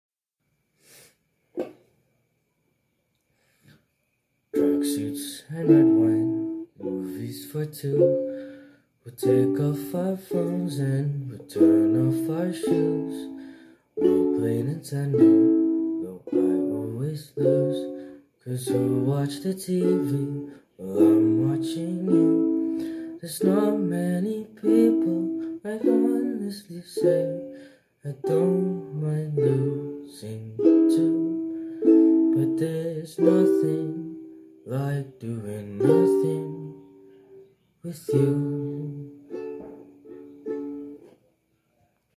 ukelele